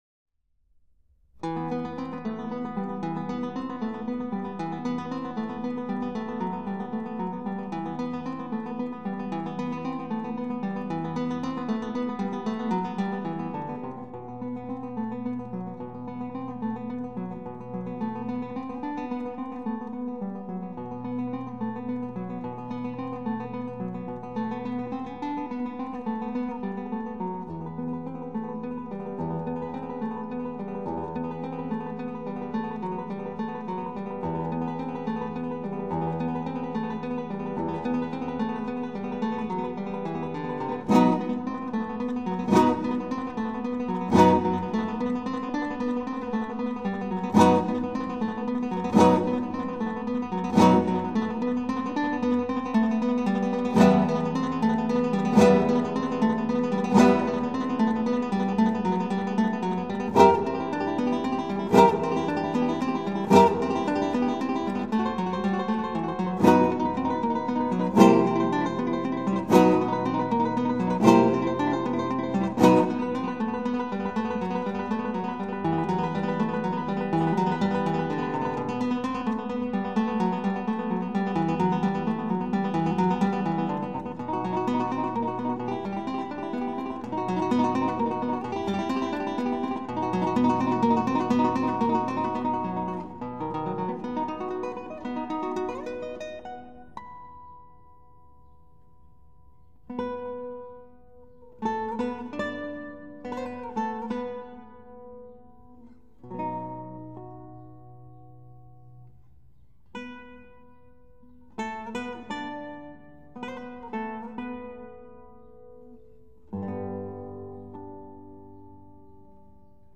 主要以吉它独奏曲广为流传。
中轮扫奏法的运用，增强了乐曲前后段落强烈的节奏性和戏剧性。中间部主题的性格与前后部分形成鲜明对比。在徐缓速度上吟唱的中间部主旋律充满内在的激情。